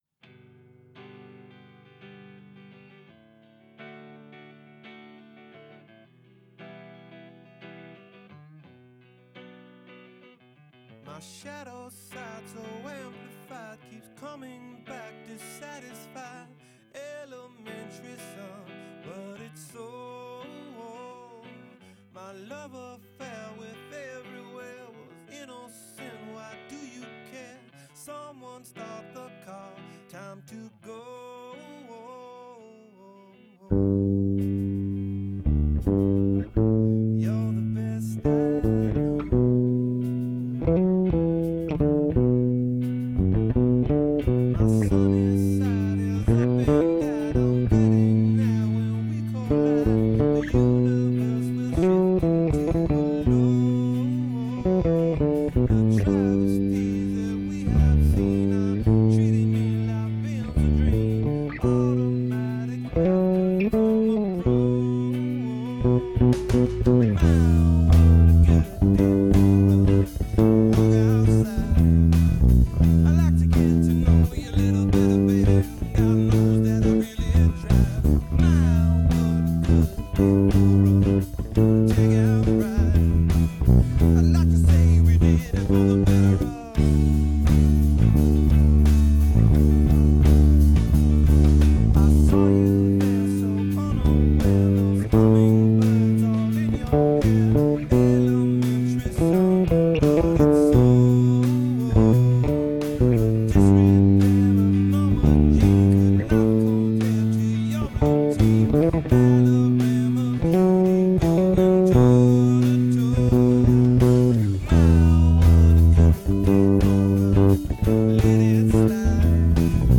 Instrumental / Bass only